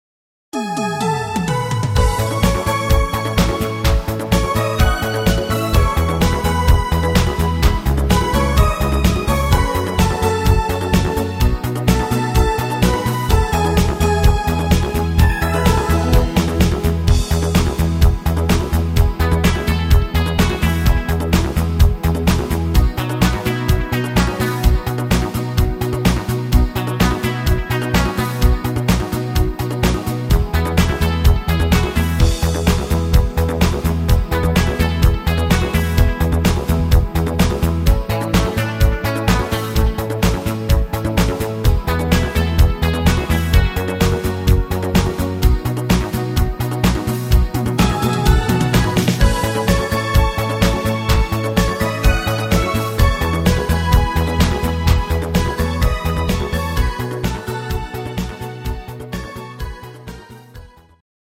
Rhythmus  Discofox
Art  Deutsch, Schlager 90er